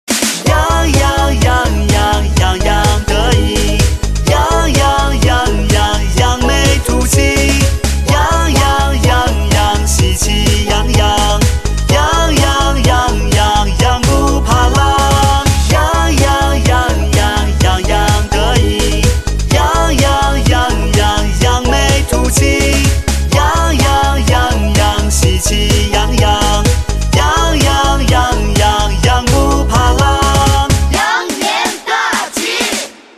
M4R铃声, MP3铃声, 华语歌曲 138 首发日期：2018-05-15 10:37 星期二